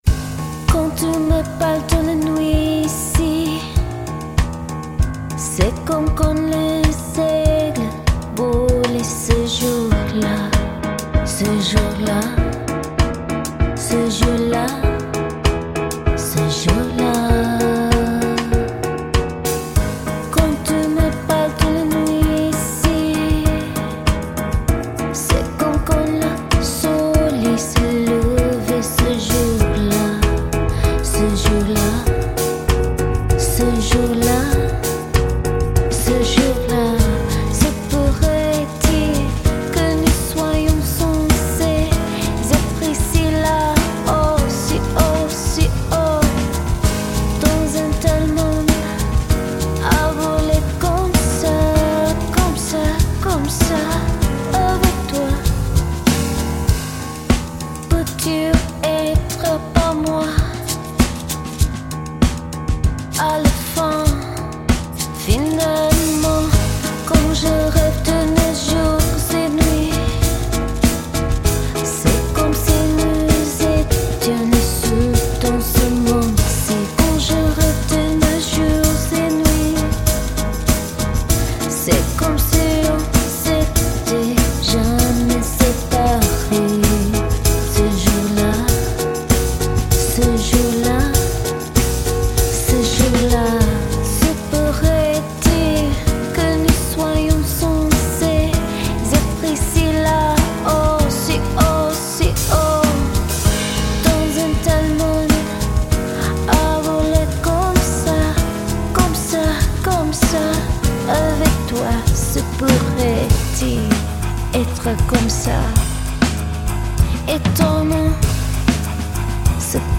downtempo jazz infused indie